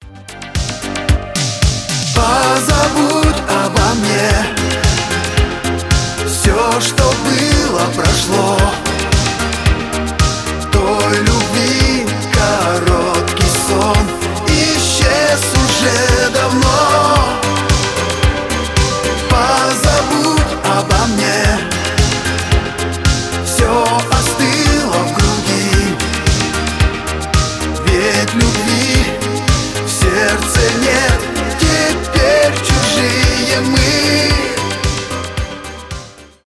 шансон , русские , диско , танцевальные , поп